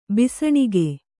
♪ bisaṇige